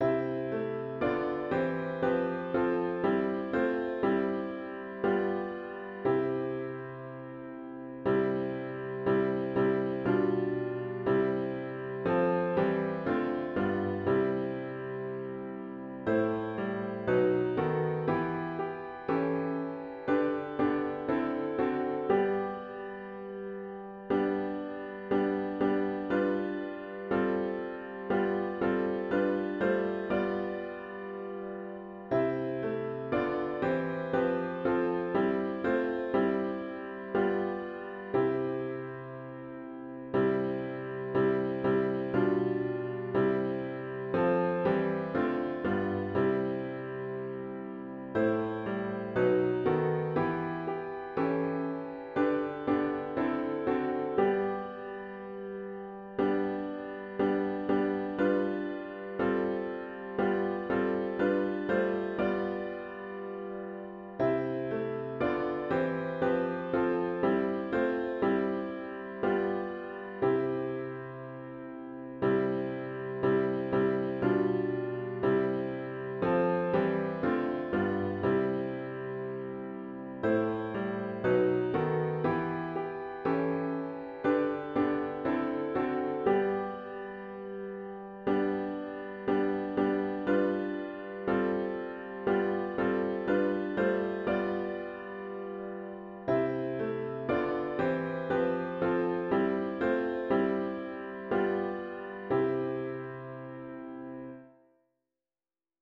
CLOSING HYMN   “The Risen Christ”   GtG 257   (Verses 1, 2, & 4)